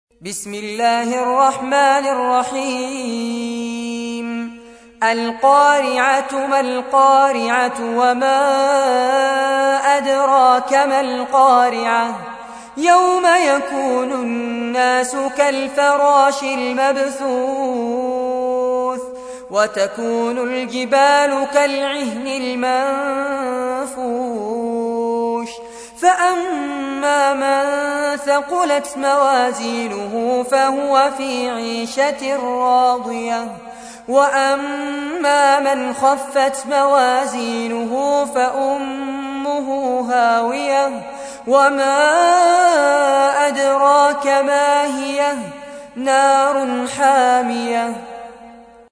تحميل : 101. سورة القارعة / القارئ فارس عباد / القرآن الكريم / موقع يا حسين